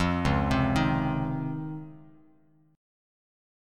DbM9 Chord